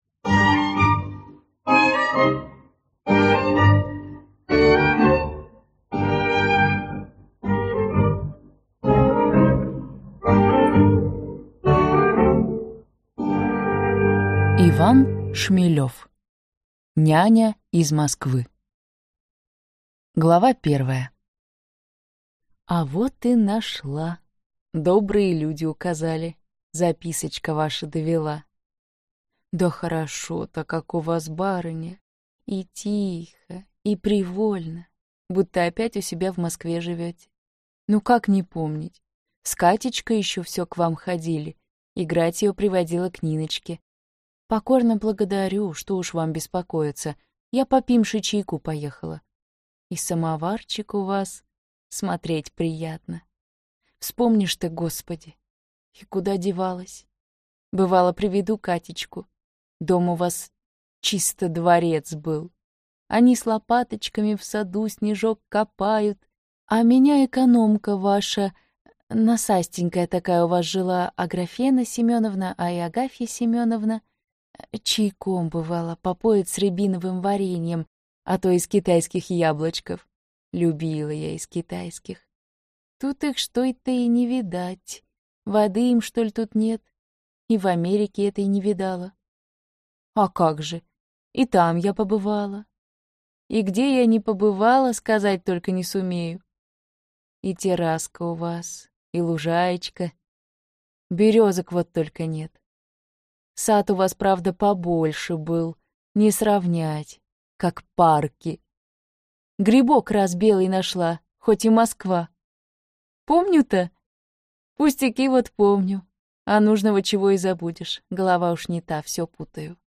Аудиокнига Няня из Москвы | Библиотека аудиокниг